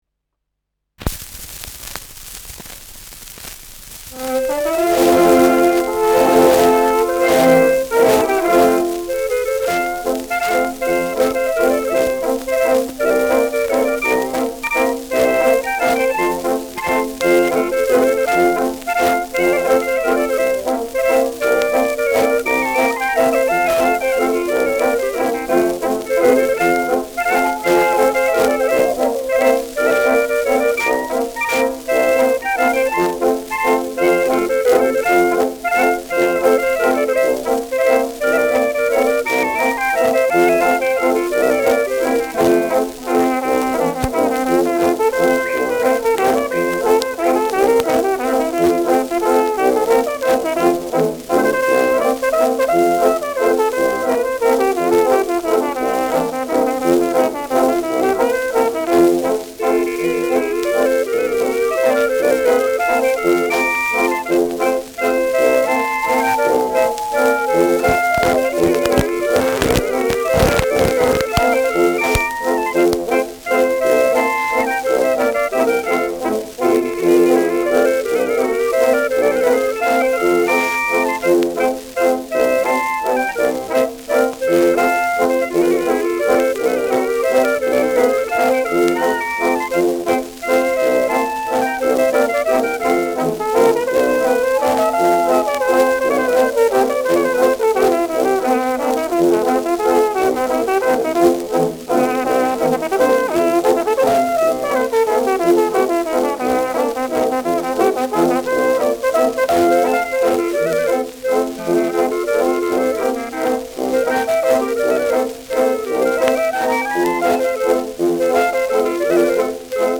Schellackplatte
präsentes Rauschen : leichtes Knistern : vereinzeltes Knacken : Knacken bei 1’07’’ : Knacken und „Hängen“ im Schlussakkord
Dachauer Bauernkapelle (Interpretation)
Mit Pfiffen und Juchzern.